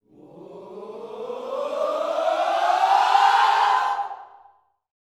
SWHOOPS 3.wav